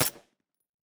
Divergent / mods / JSRS Sound Mod / gamedata / sounds / weapons / _bolt / 762_1.ogg